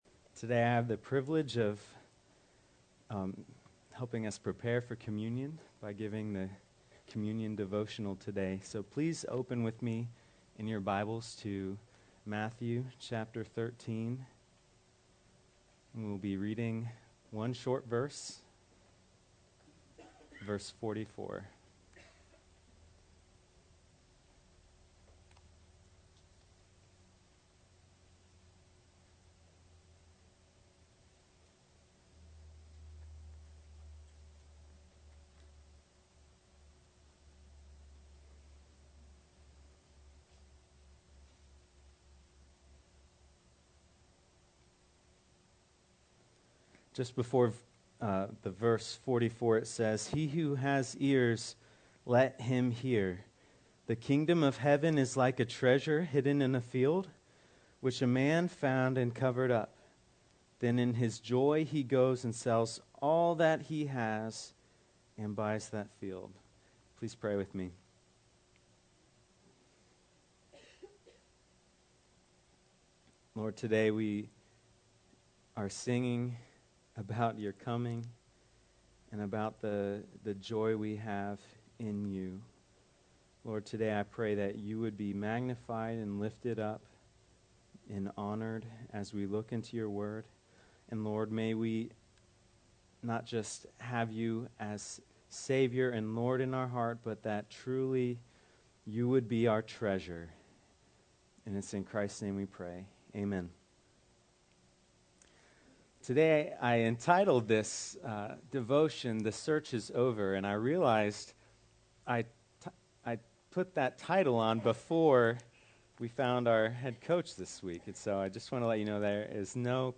teaches from the series: Topical, in the book of Matthew, verse 13:44